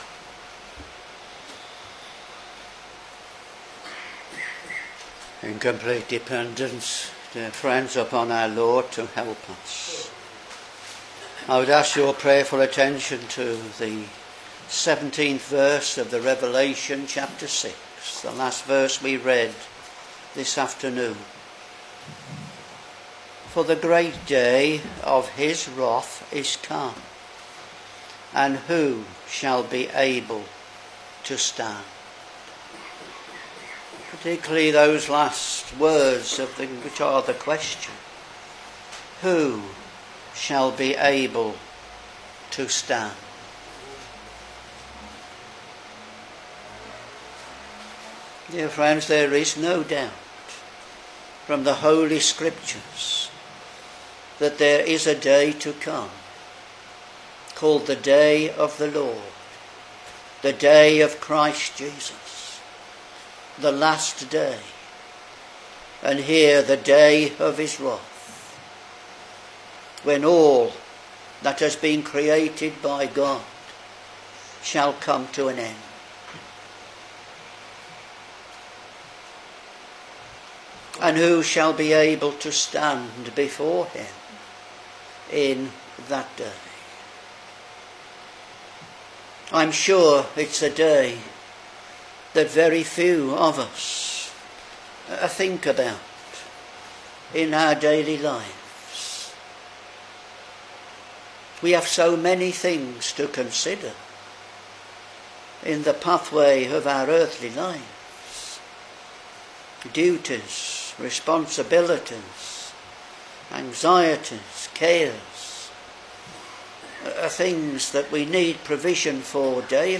Back to Sermons Revelation Ch.6 v.17 (in particular the question in 2nd part of verse)